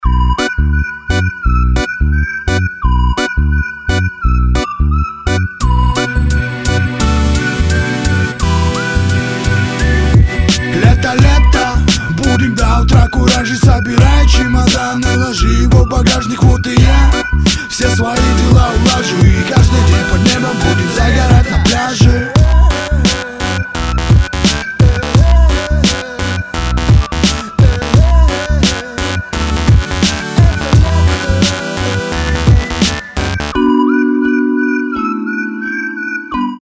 • Качество: 320, Stereo
ритмичные